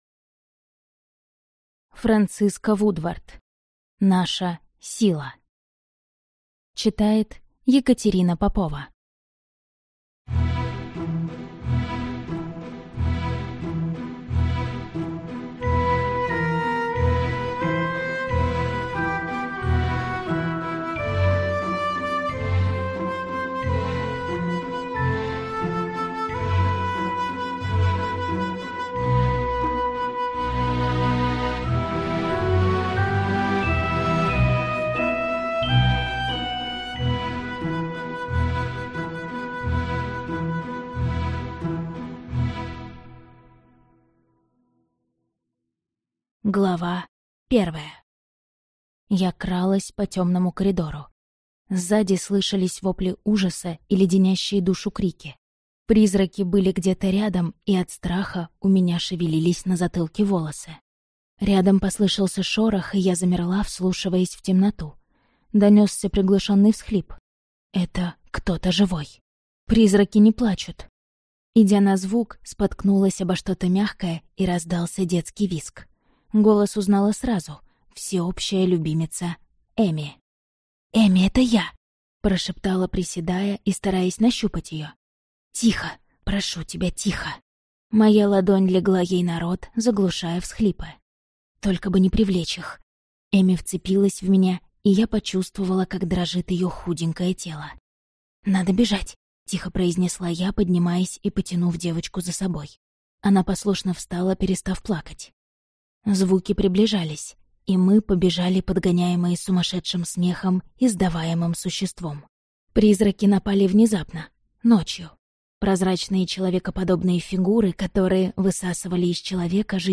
Аудиокнига Наша сила | Библиотека аудиокниг